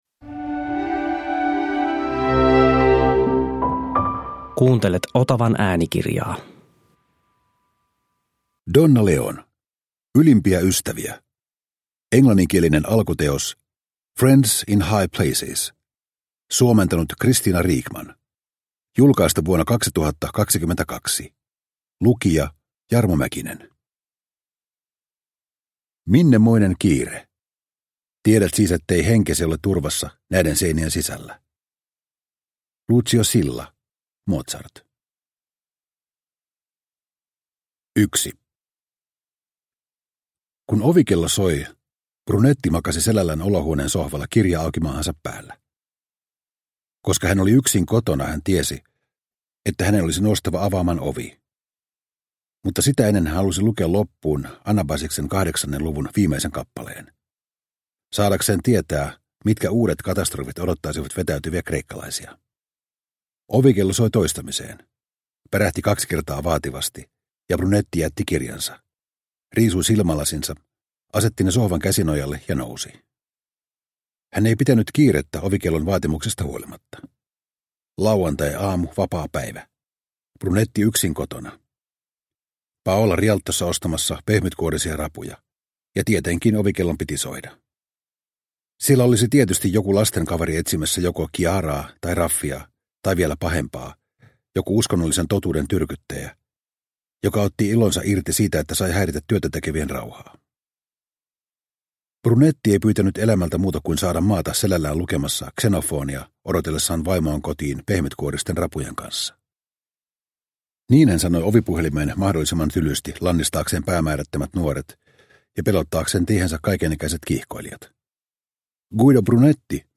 Ylimpiä ystäviä – Ljudbok – Laddas ner